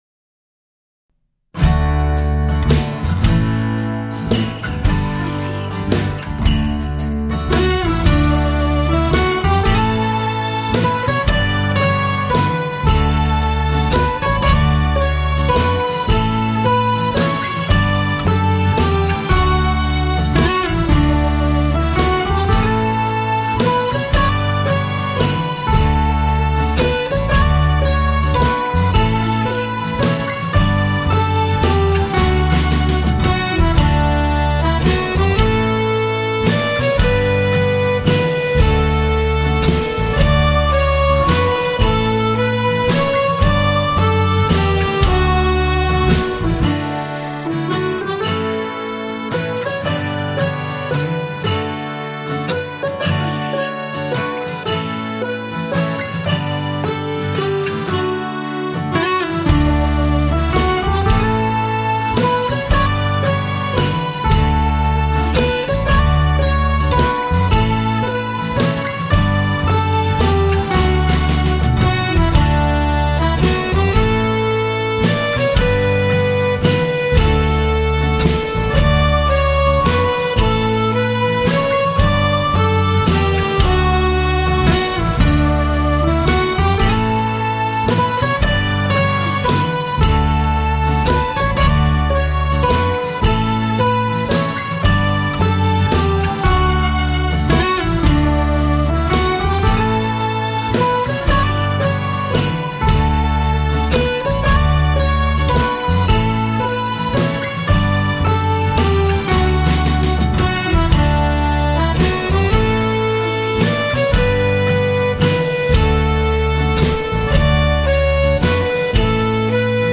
Acoustic version